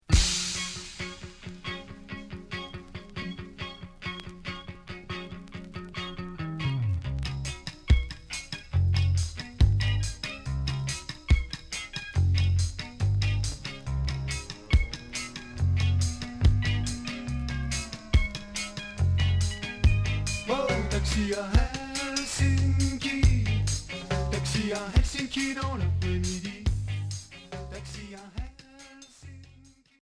New wave Deuxième 45t retour à l'accueil